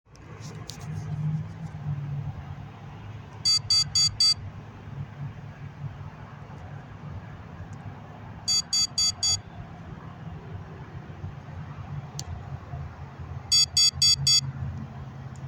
HFUN FLY'S LOW BATTERY WARNING SOUND